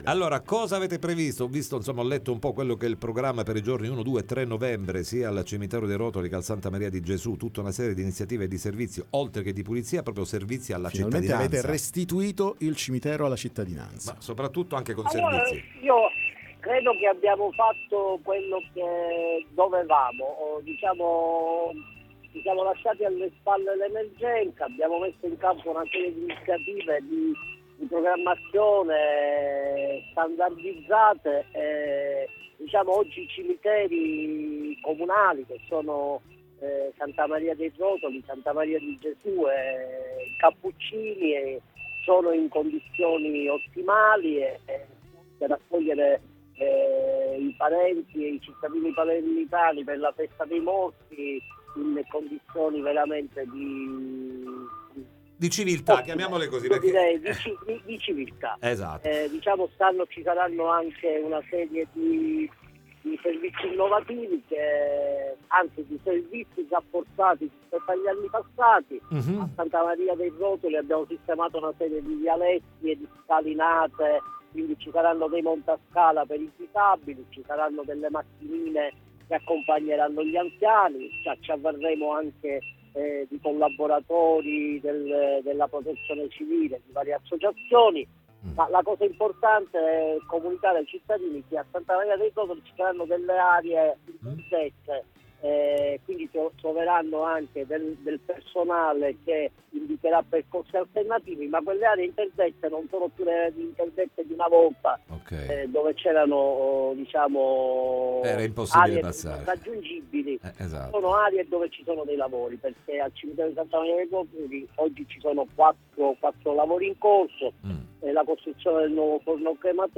Servizi rafforzati e cimiteri in condizioni ottimali in vista del weekend di Ognissanti. L’Assessore ai Servizi cimiteriali Totò Orlando, intervenuto durante la puntata del Time Magazine di oggi, 31 ottobre, ha sottolineato i lavori svolti nei cimiteri di Sant’Orsola e dei Rotoli.